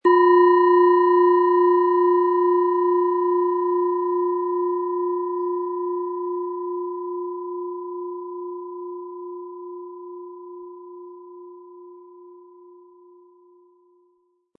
Planetenton 1
Wasser Planetenton-Klangschale, handgefertigt.